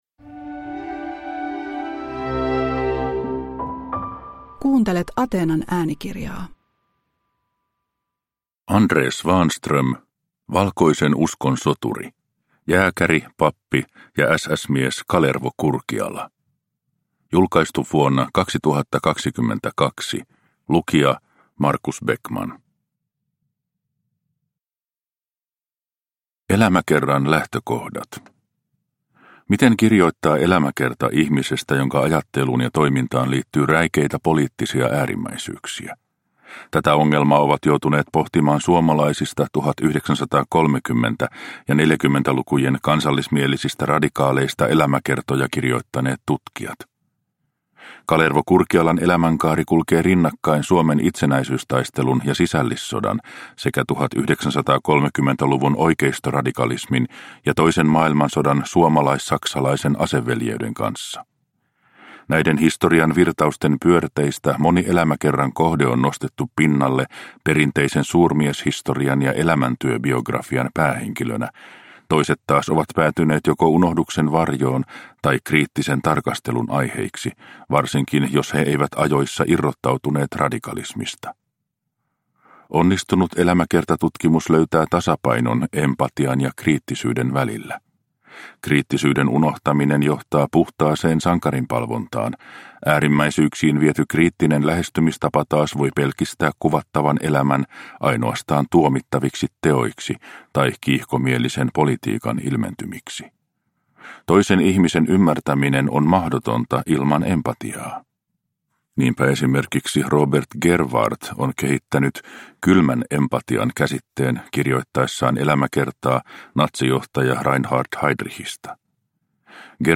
Valkoisen uskon soturi – Ljudbok – Laddas ner